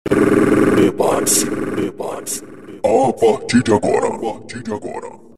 VINHETAS !